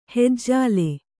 ♪ hejjāle